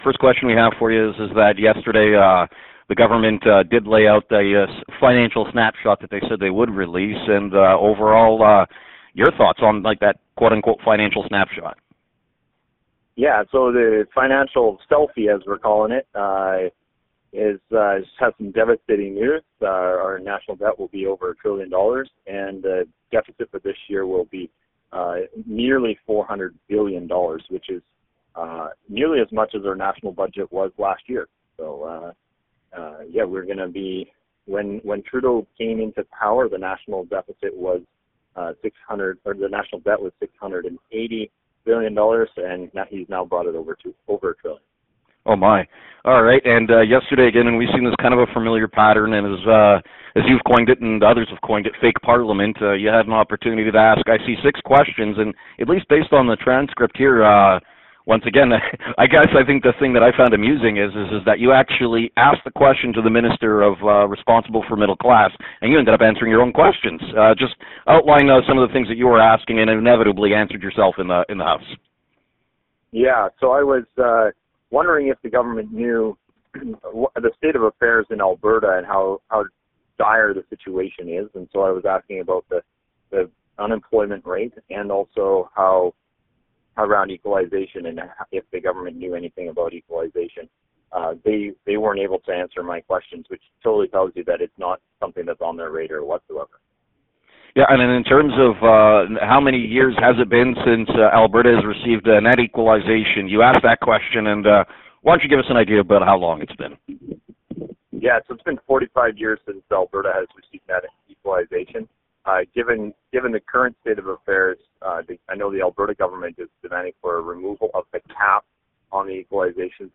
Peace River-Westlock MP, Arnold Viersen joins the Trending 55 Newsroom from Ottawa following the financial snapshot given in the House of Commons yesterday.